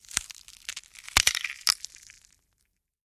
Здесь собраны уникальные аудиозаписи, воссоздающие атмосферу древних гробниц: от приглушенных стонов до зловещего шелеста бинтов.
Хруст костей мумии